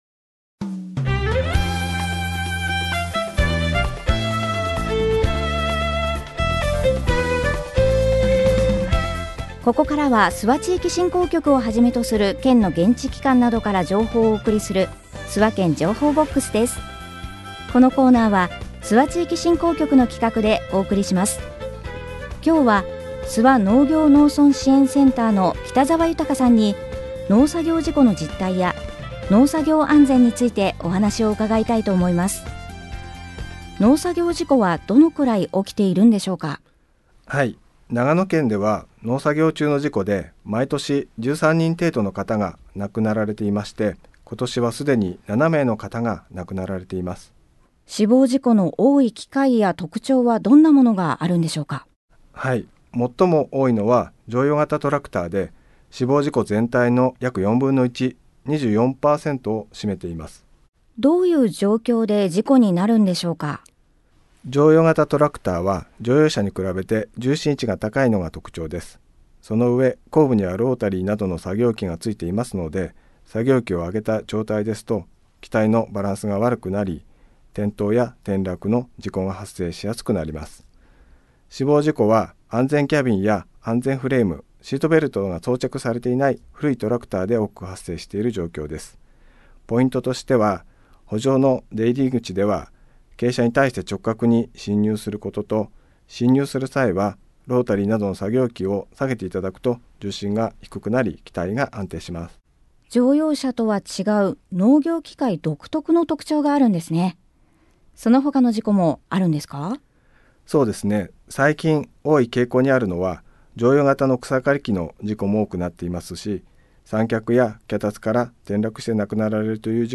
コミュニティエフエムを活用した地域情報の発信